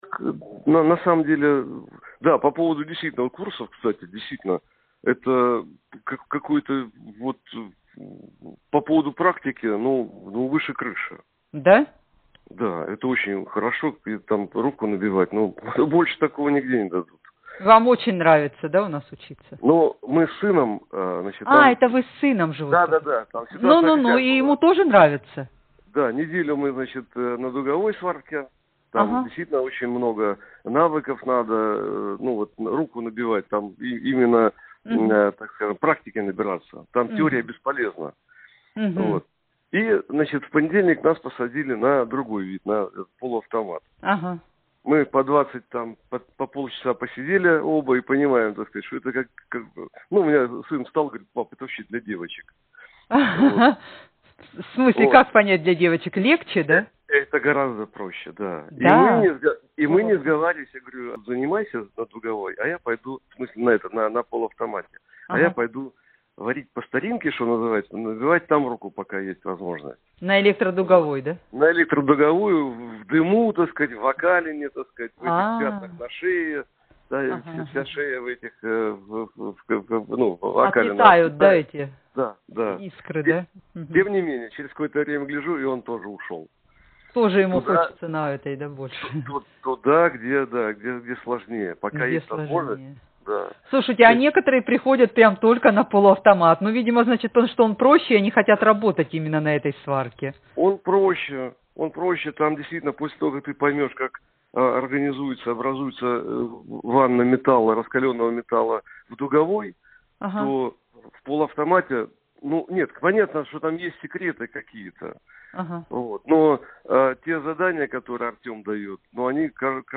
Аудио Отзывы